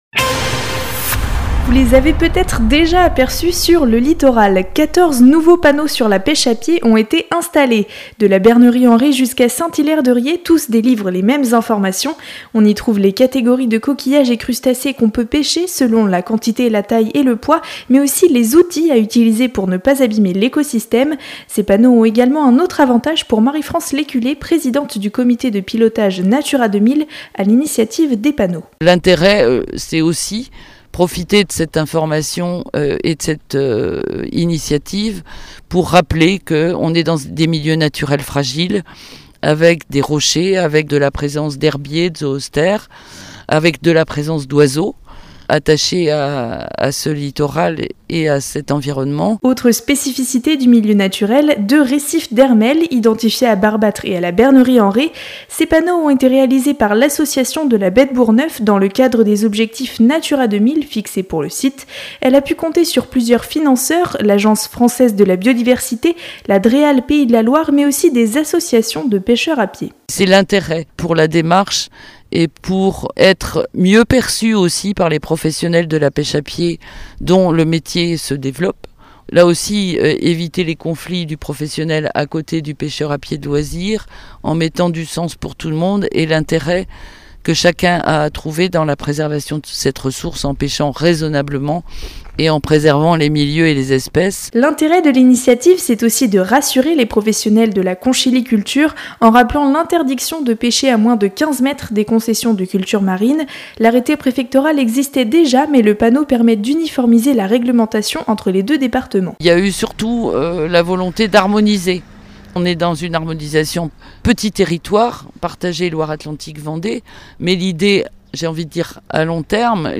Reportage radio NOV FM